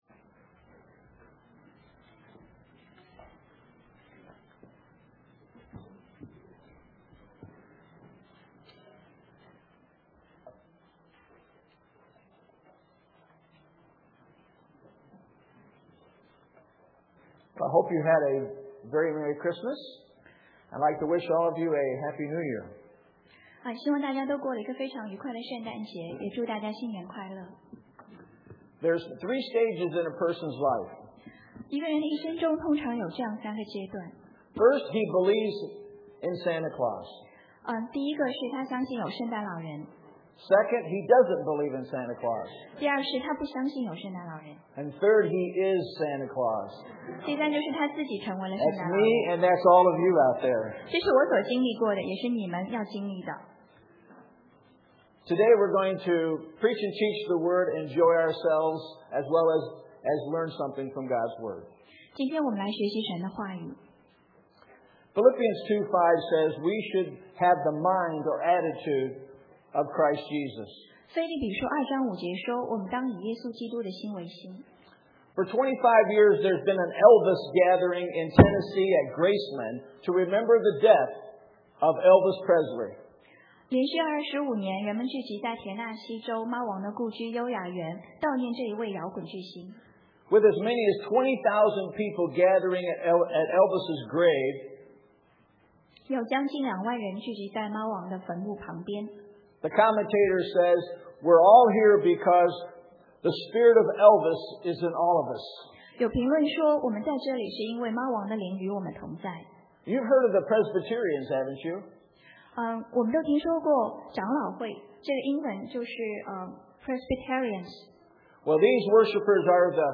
英文讲道